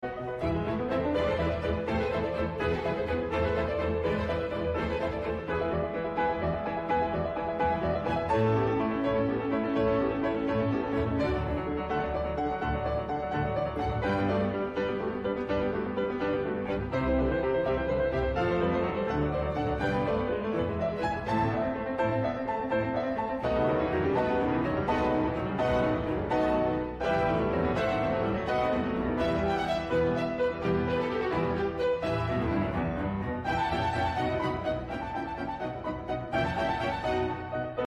on stage